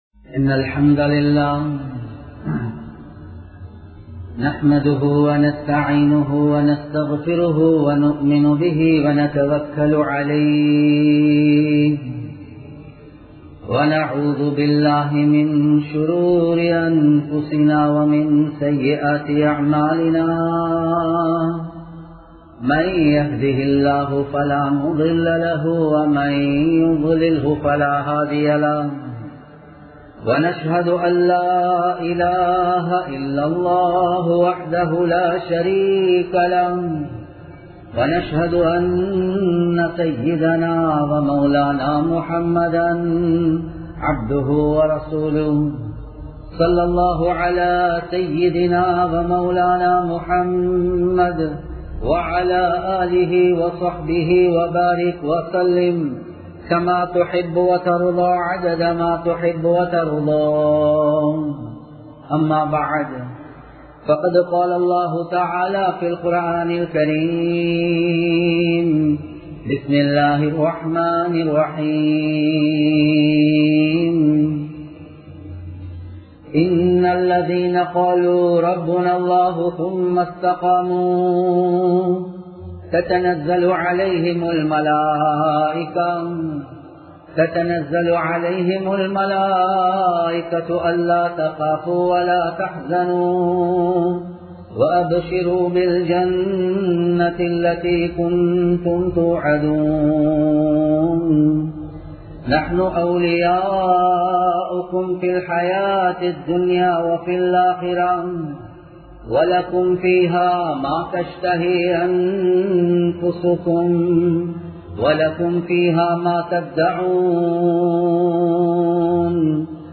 மனிதனின் இறுதி நிலை | Audio Bayans | All Ceylon Muslim Youth Community | Addalaichenai
Sugathadasa Indoor Stadium Jumua Masjidh